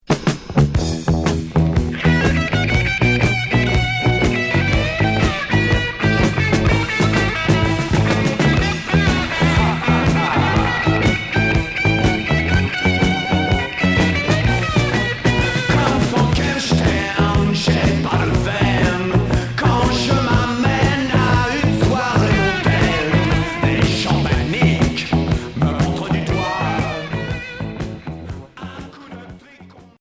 Rock boogie